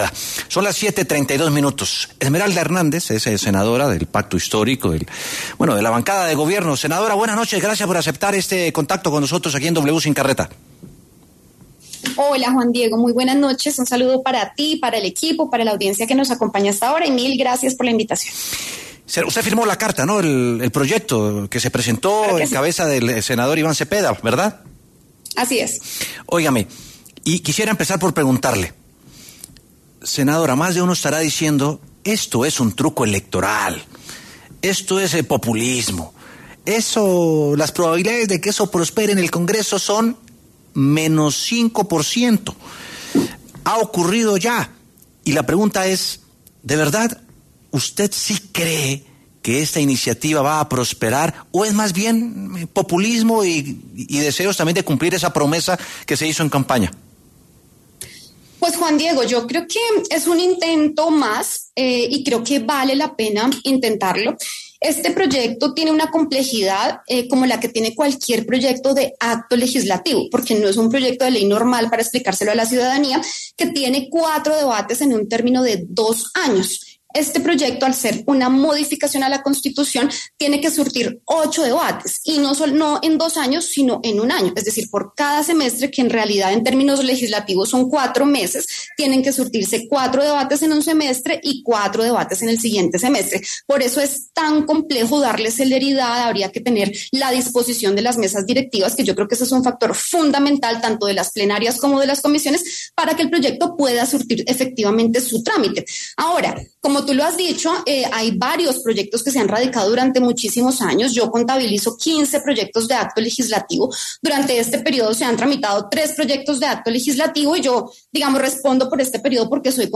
Esmeralda Hernández, senadora del Pacto Histórico, conversó con W Sin Carreta sobre este nuevo proyecto recordando los motivos que han llevado a su fracaso en anteriores ocasiones.